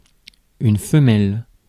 Ääntäminen
Ääntäminen France: IPA: [fə.mɛl] Haettu sana löytyi näillä lähdekielillä: ranska Käännös Konteksti Substantiivit 1. hembra {f} Adjektiivit 2. hembra {f} 3. femenino {m} biologia Suku: f .